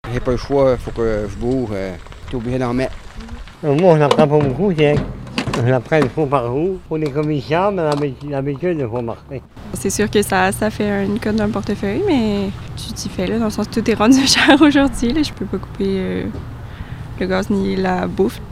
Les automobilistes interrogés jeudi matin en train de faire le plein se résignent à cette nouvelle réalité chère payée: